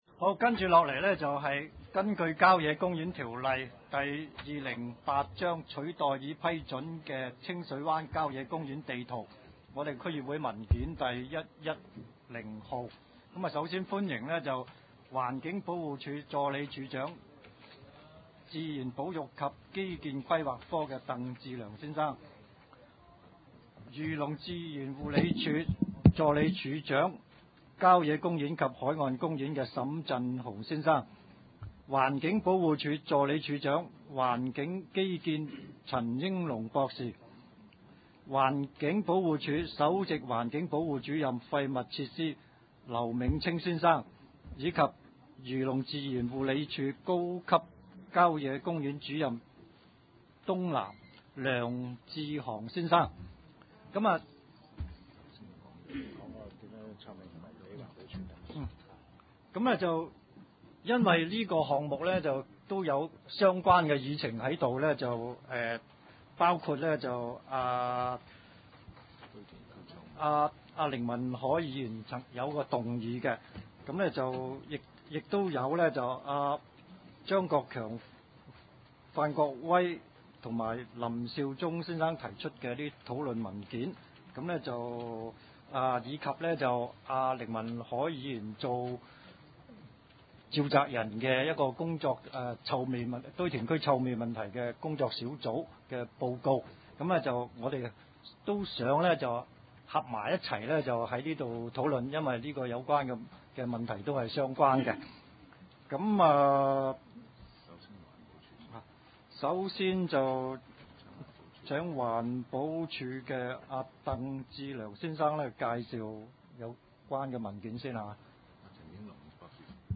西貢區議會第 八次會議